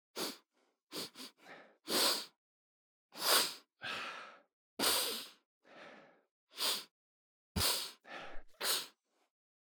Звуки вынюхивания аромата
нюхает